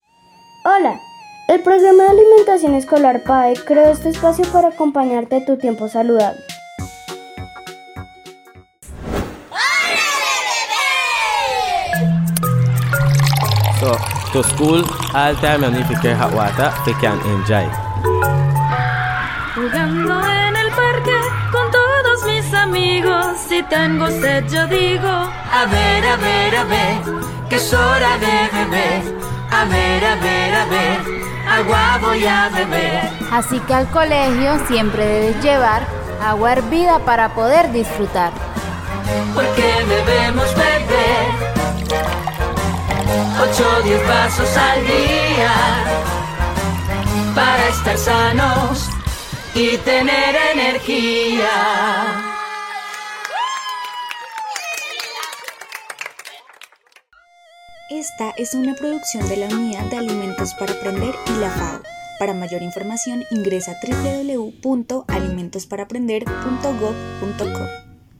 Copla Agua 4.mp3